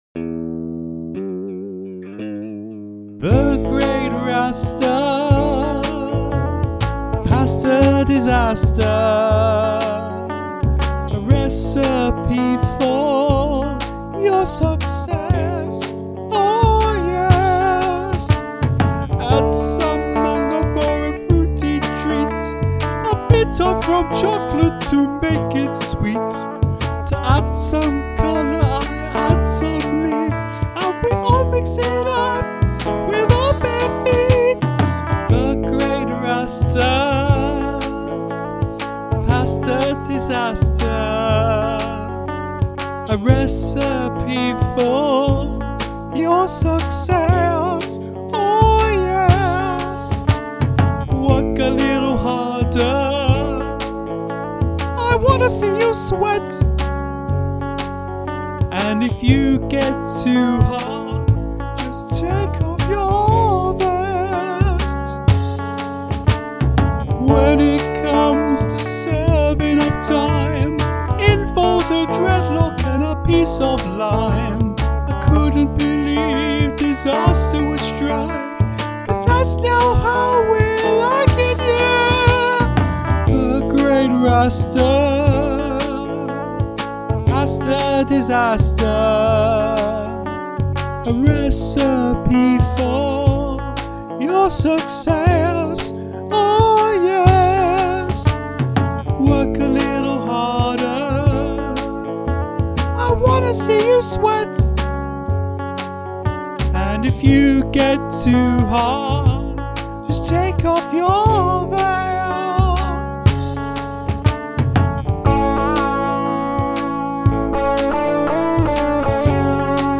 We have here a pure fusion.